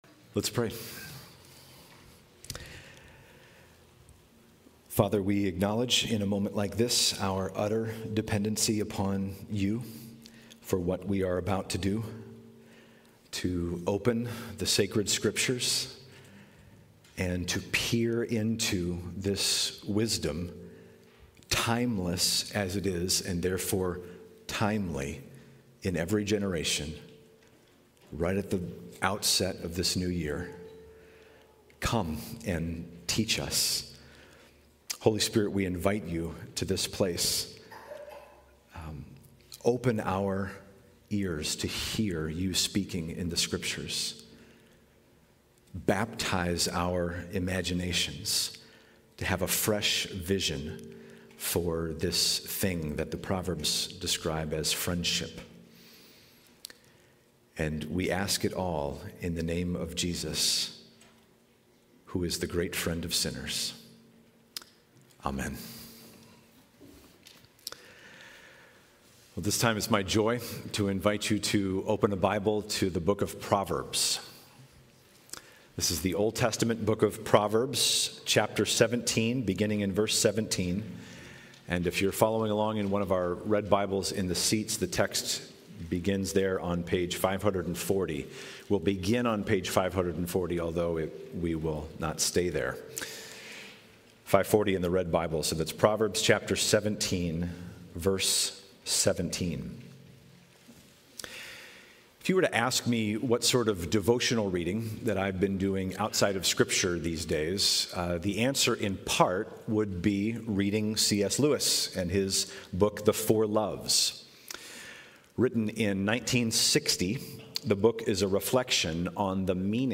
2024 Standalone Sermons